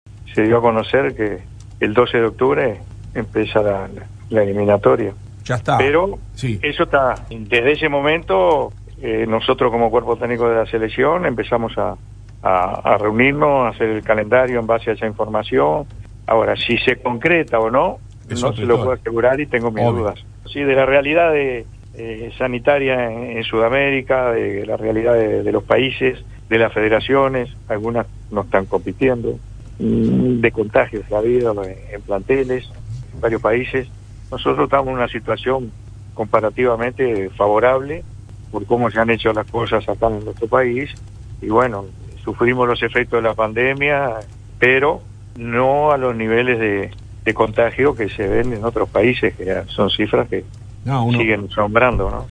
CRÉDITO: Óscar Tabárez, seleccionador de Uruguay, en entrevista con Radio Sport 890 de Montevideo